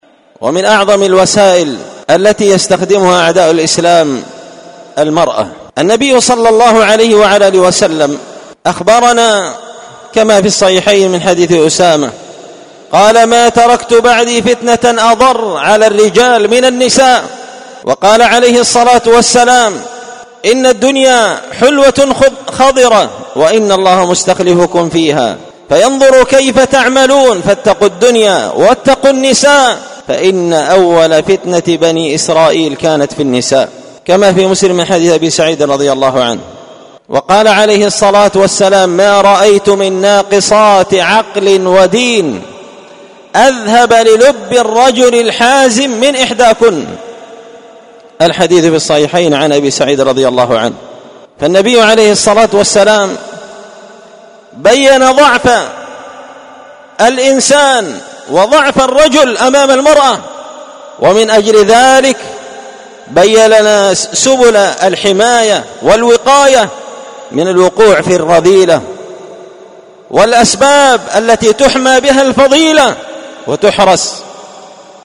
سلسلة مقتطفات من خطبة جمعة بعنوان حراسة الفضيلة وحماية المجتمع من الرذيلة ⏸المقتطف الثالث⏸السبب الأول من الأسباب التي تحرس وتحمى بها الفضيلة حماية المرأة وصيانتها من الأعداء
دار الحديث بمسجد الفرقان ـ قشن ـ المهرة ـ اليمن